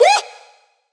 KSHMR_Vocal_Shout_31_Wahp_G#
KSHMR_Vocal_Shout_31_Wahp_G.wav